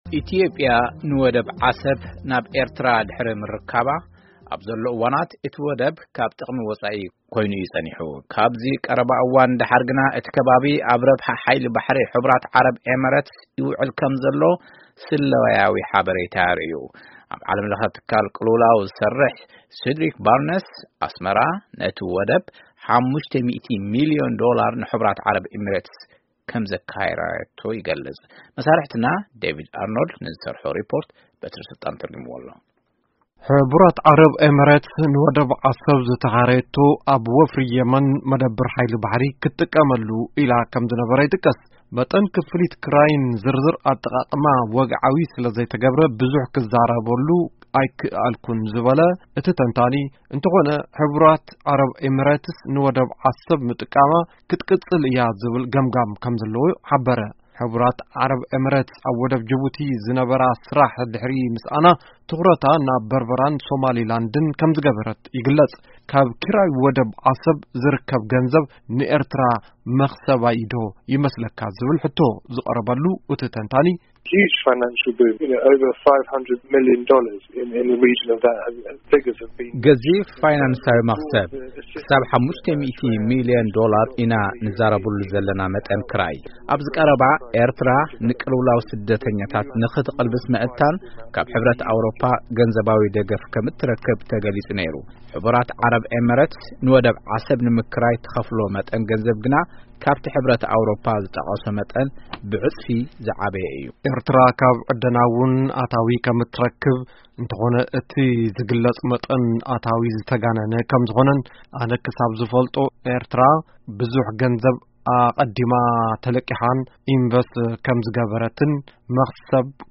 ሪፖርት ብዛዕባ ኪራይ ዓሰብ